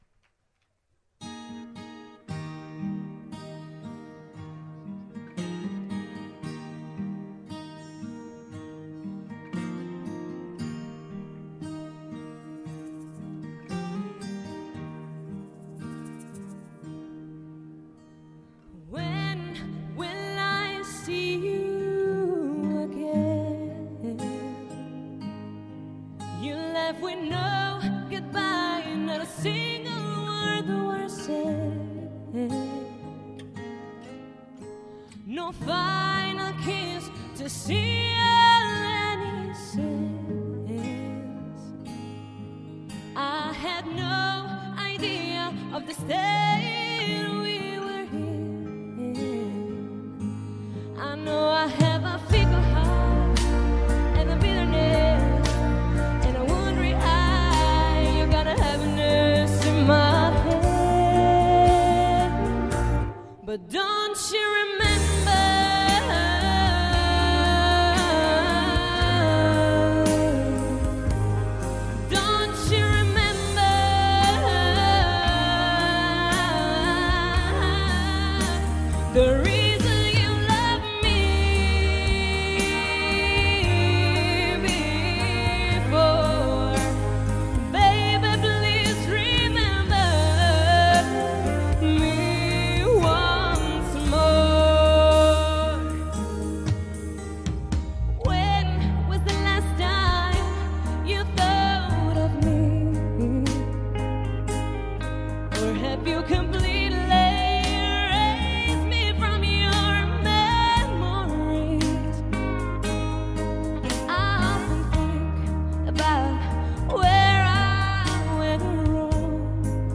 Kantabeach 10° Edizione 2013.